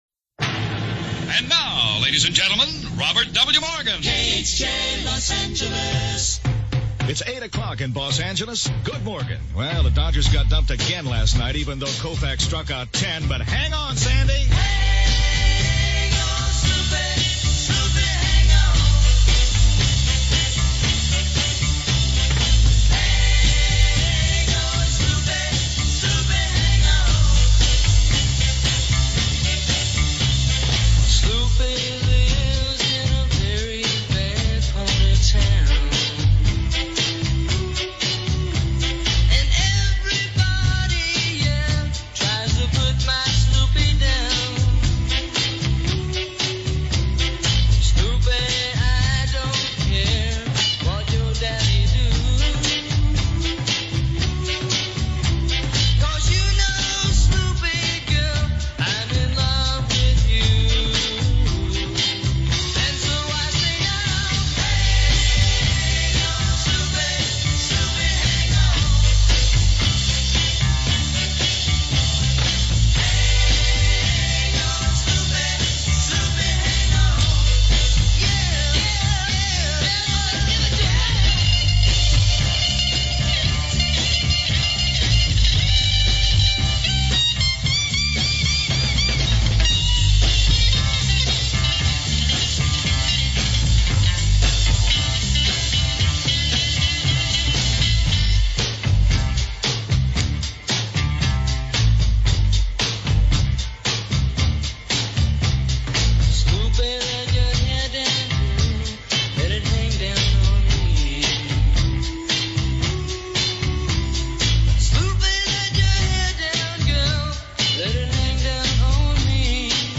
Morgan was known for his engaging and energetic personality, often incorporating humor and a friendly, approachable style into his broadcasts. The station’s programming included extensive music playlists, a strong emphasis on top 40 hits, and catchy jingles (the famous “Boss Radio” jingle became iconic). The sound was edgy, fun, and fast-moving, with DJs like Robert W. Morgan, The Real Don Steele , and Charlie Tuna becoming beloved figures on the airwaves.
We’ve added this aircheck to the USA Radio Museum’s aircheck repository.
This audio recording was digitally enhanced by USA Radio Museum.